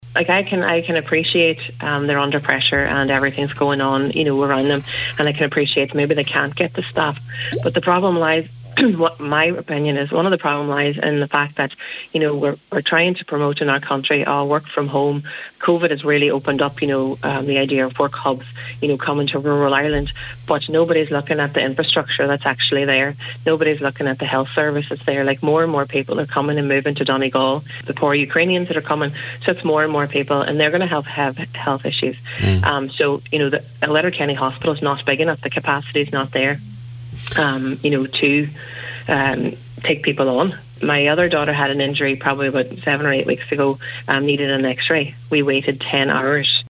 Speaking on the Nine Till Noon Show